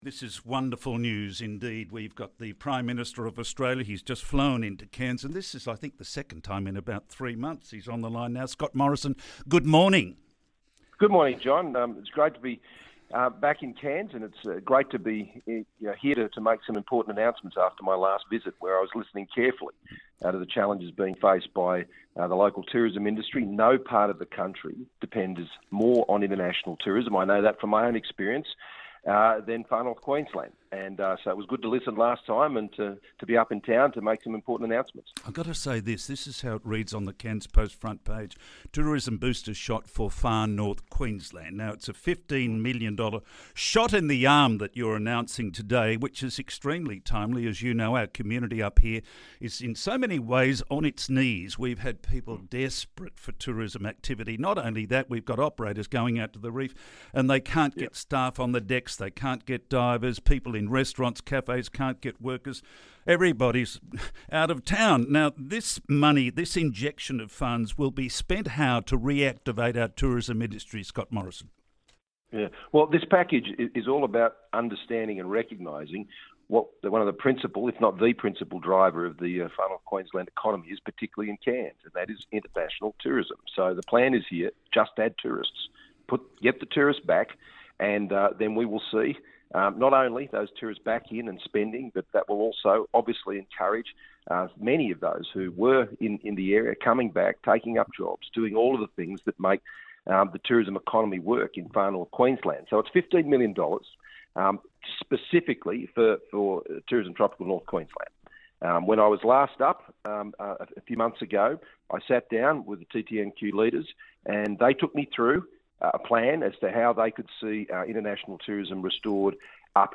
Today I spoke with Scott Morrison, Prime Minister of Australia, about federal funding being allocated to Tourism Tropical North Queensland. We also touched on the state of Cairns CBD and the need for a new sports stadium.